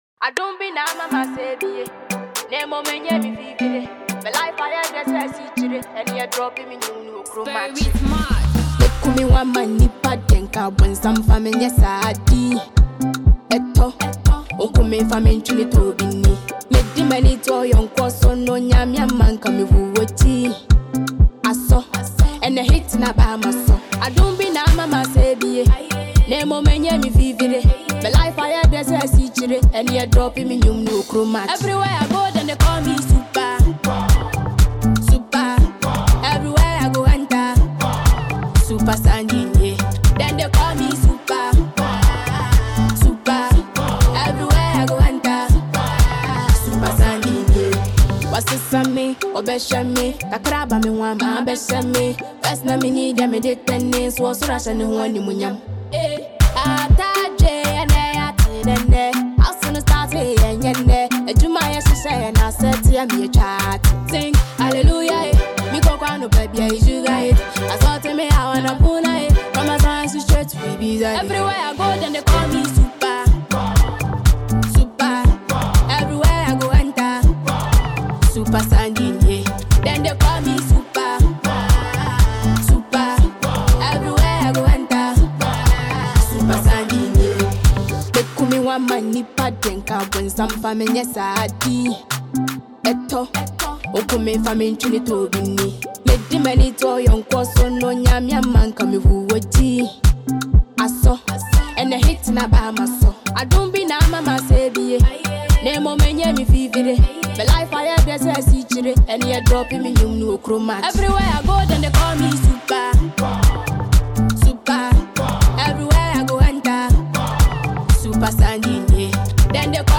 Young and talented Ghanaian female musician